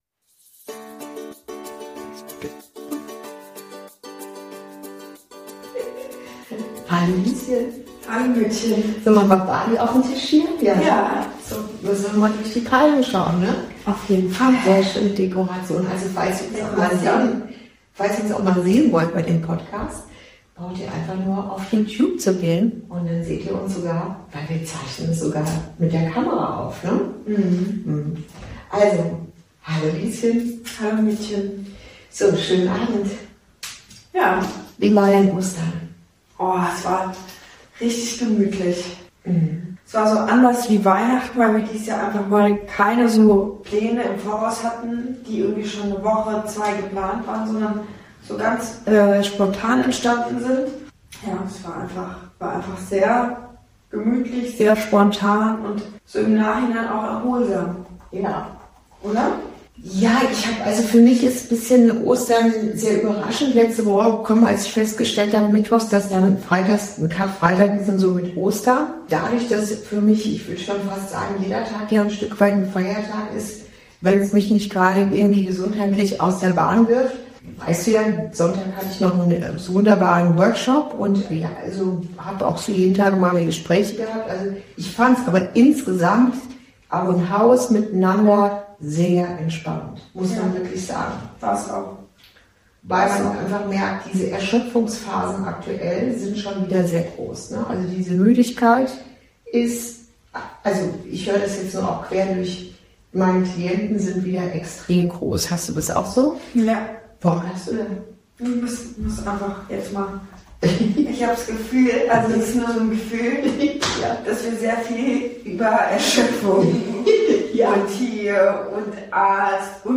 Folge 10: Zwischen Osterhase und Berufung ~ Inside Out - Ein Gespräch zwischen Mutter und Tochter Podcast